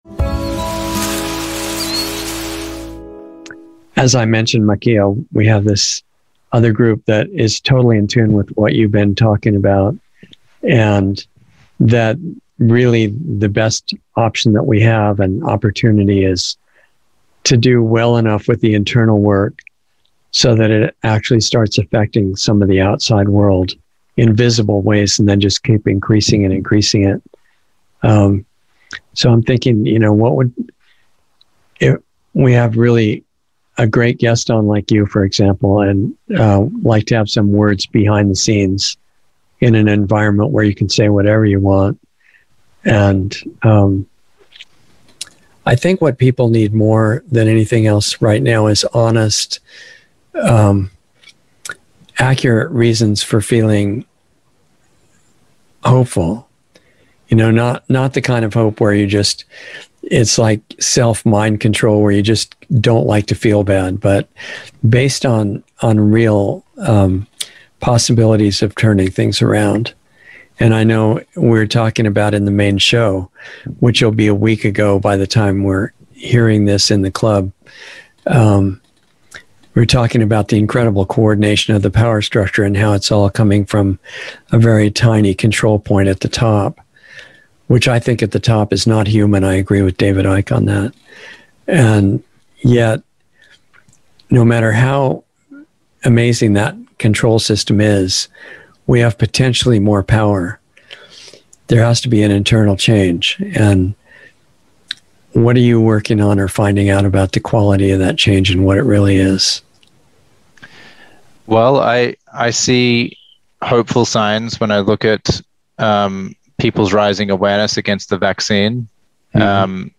Insider Interview 6/2/21